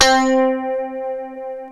L-A   GUITAR 2.wav